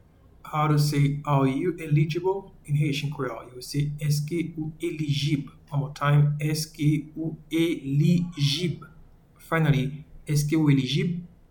Pronunciation:
Are-you-eligible-in-Haitian-Creole-Eske-ou-elijib.mp3